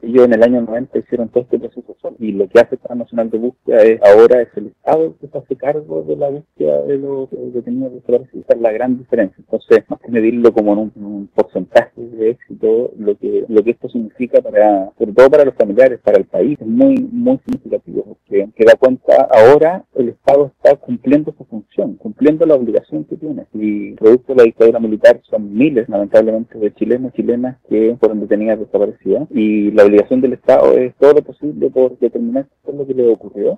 “Estamos en el marco de las diligencias que se están desarrollando acá en esta zona desde la semana pasada y que continúan esta semana por el Plan Nacional de Búsqueda, con el objetivo de poder determinar la verdad de lo que ocurrió y ojalá poder encontrar a los detenidos desaparecidos”, explicó el ministro Gajardo en conversación con Radio Paulina.